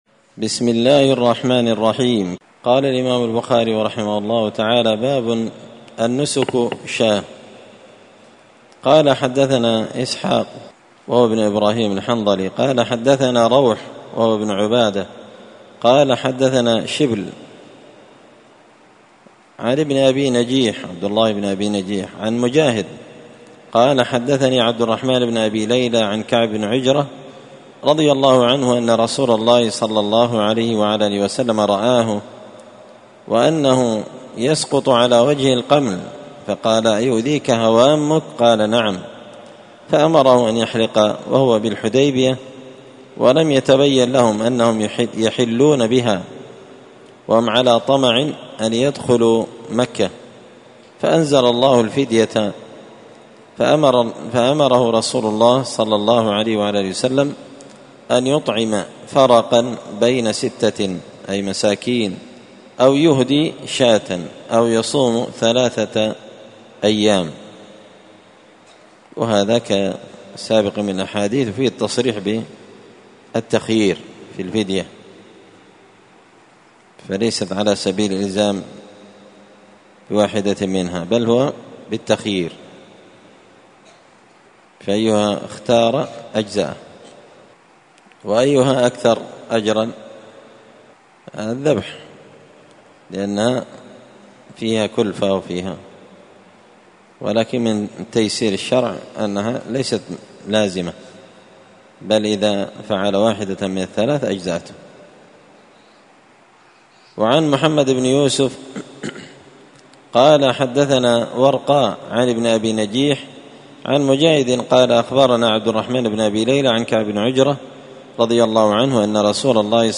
كتاب المحصر من شرح صحيح البخاري- الدرس 8 باب النسك شاة